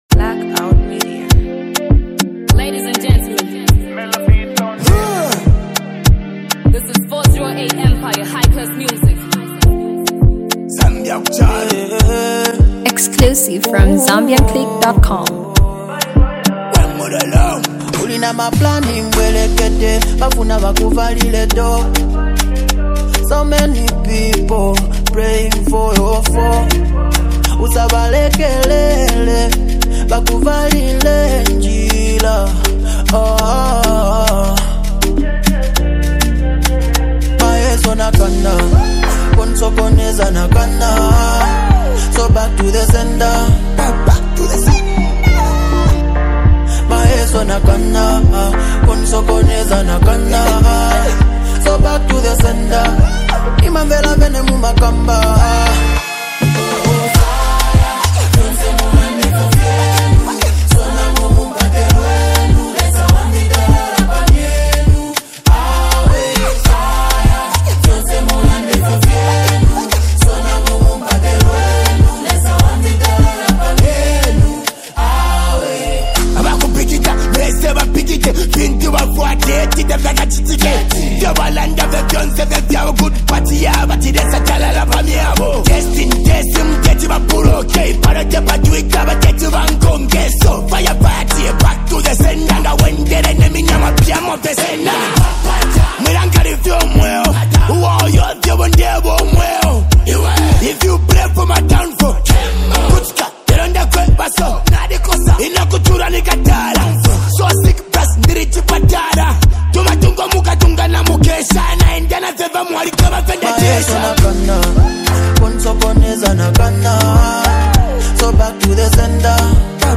street anthem
a hot hook to the song